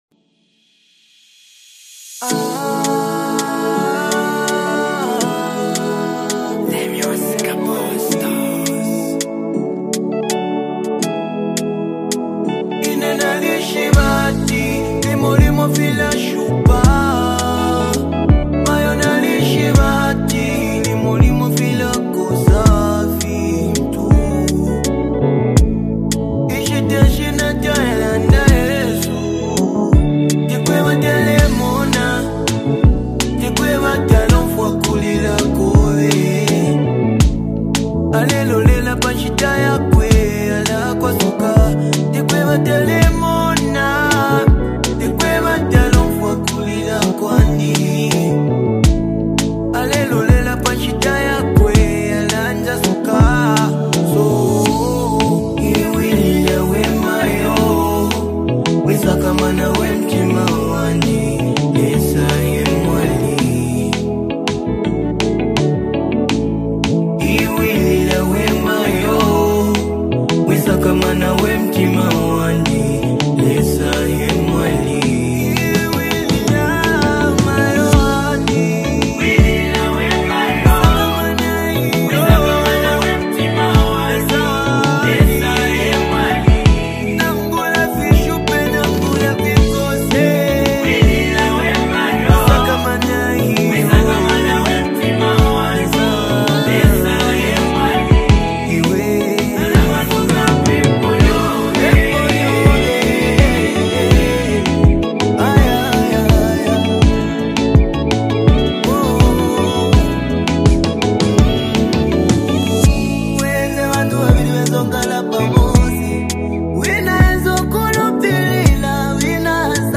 Through soothing melodies and Scripture-inspired lyrics